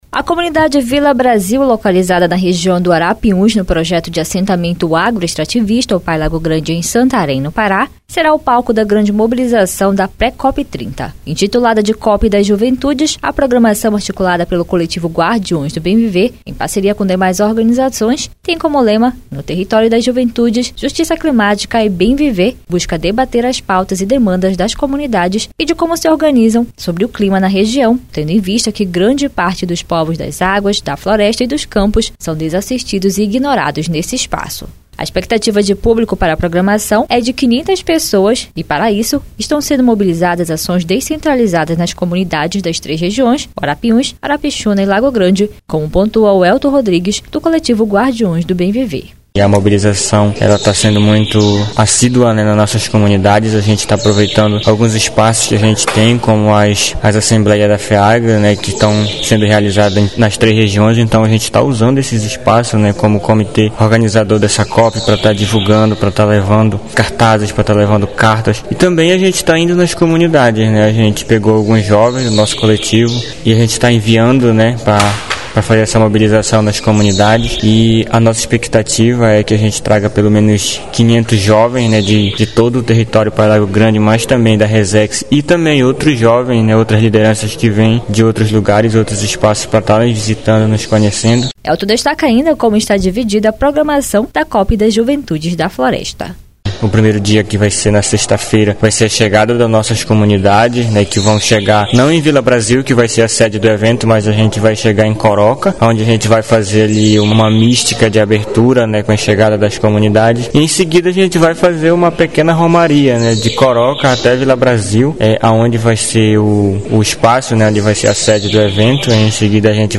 Com romaria e seminários, articulada pelo Coletivo Guardiões do Bem Viver, a programação acontece no período de 23 a 25 de maio, na Comunidade Vila Brasil. A reportagem